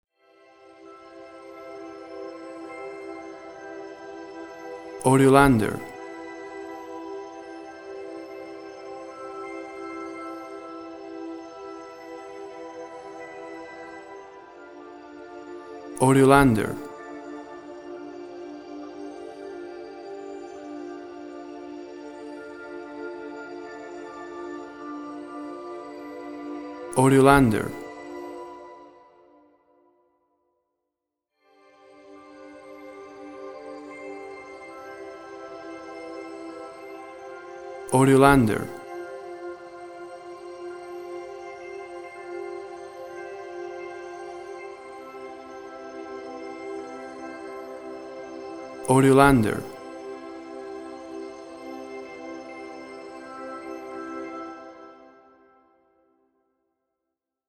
Melodic space music — vast, warm, soothing (treble range).
Tempo (BPM): 60